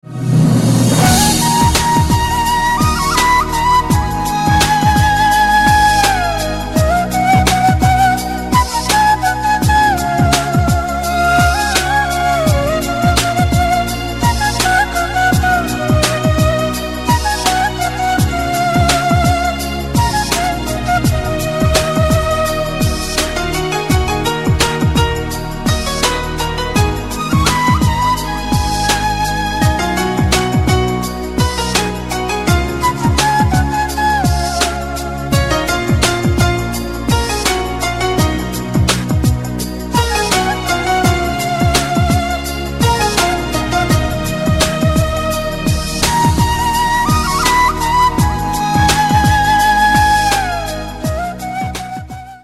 инструментальные
Флейта